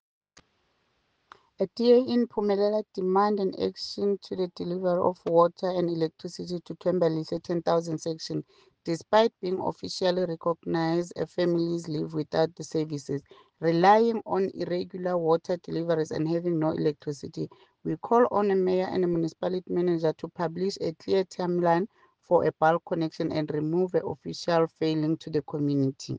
English soundbite by Cllr Ntombi Mokoena and Sesotho soundbite by Jafta Mokoena MPL.